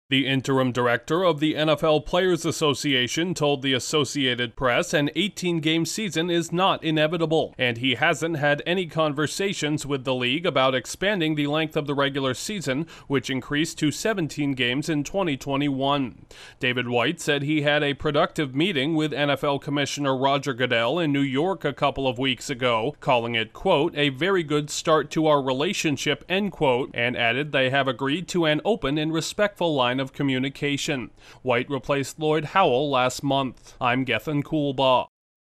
The acting top executive for NFL players says additional regular season games may not be on the horizon. Correspondent